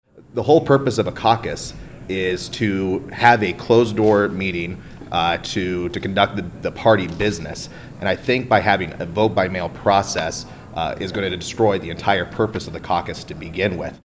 Representative Austin Harris of Moulton is the former political director for the Iowa Republican Party. He predicts New Hampshire will schedule its presidential primary ahead of Iowa’s Caucuses if Iowa Democrats are allowed to follow through with their plan.